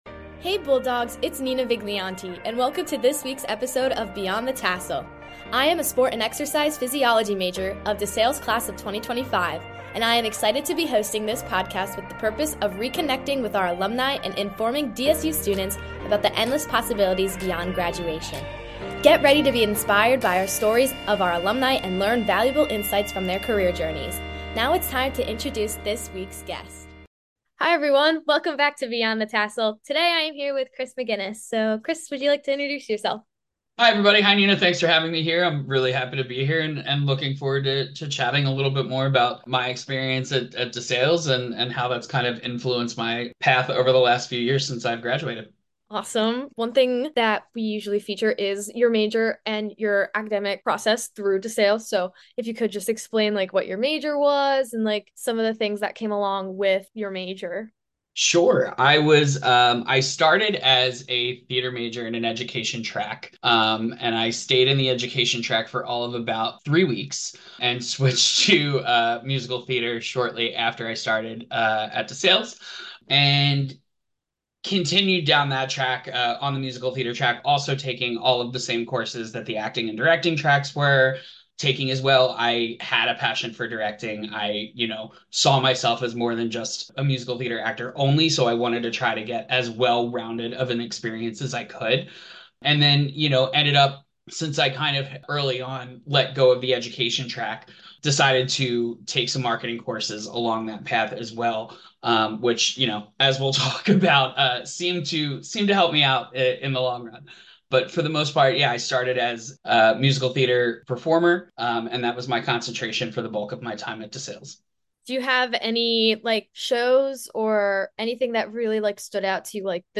Play Rate Listened List Bookmark Get this podcast via API From The Podcast Beyond the Tassel reconnects with DeSales University alumni to inspire and inform current students about the infinite opportunities that our graduates have experienced beyond college. Through informational interviews, this podcast will share stories, experiences, insights and resources to help current DeSales University students explore real world opportunities and spark further career curiosity.